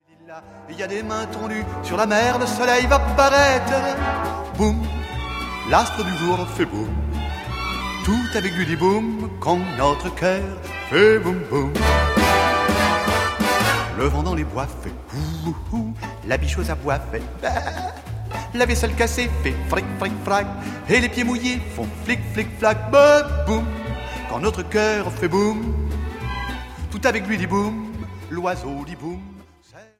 10s Pop Music CDs Soundtracks